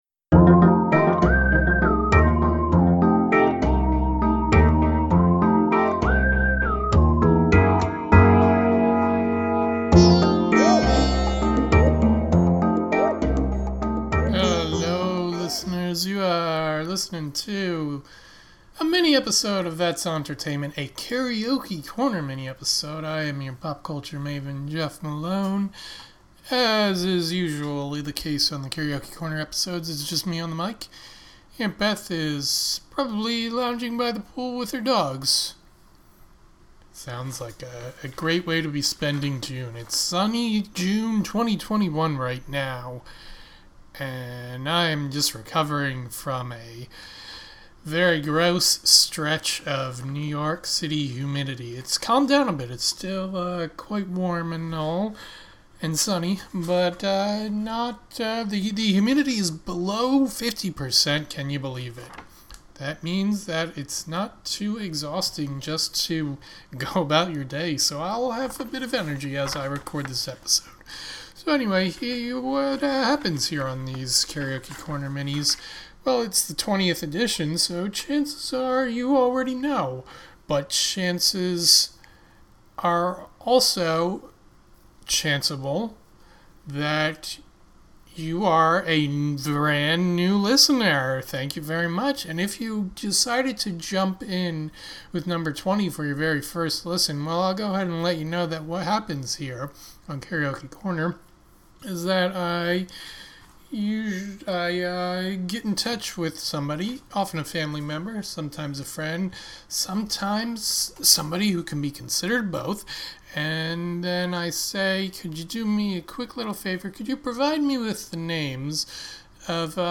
Rock ‘n’ Roll Alert!